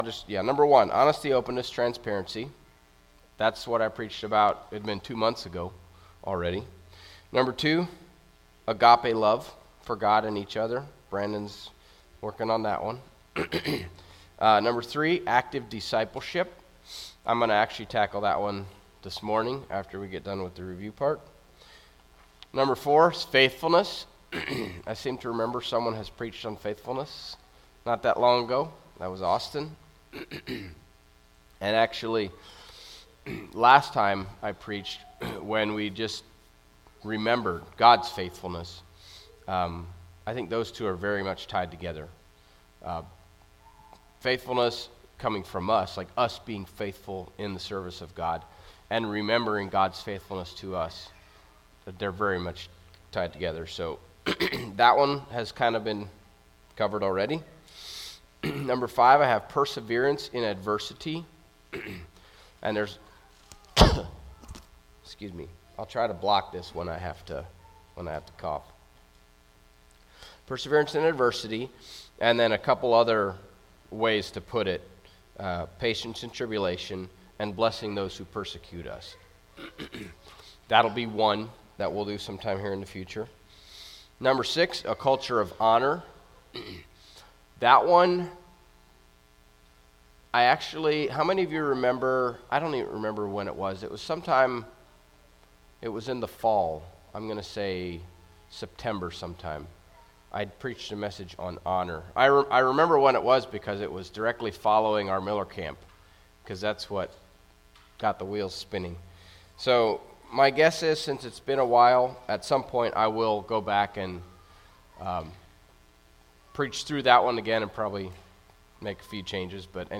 Service Type: Sunday Message